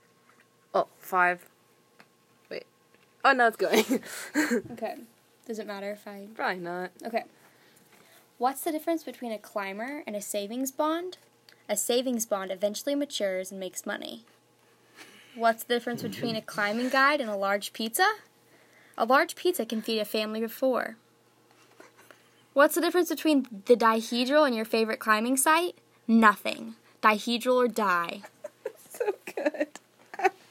Fabulous sounding voices, all of them.